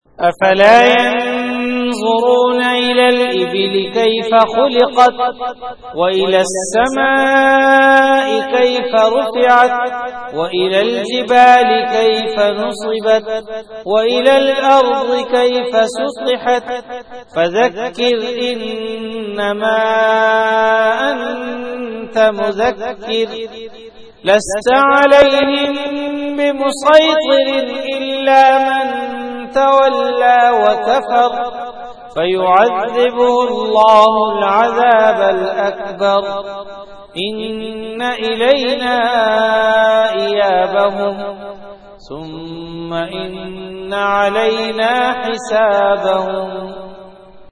CategoryTilawat
VenueJamia Masjid Bait-ul-Mukkaram, Karachi
Event / TimeAfter Isha Prayer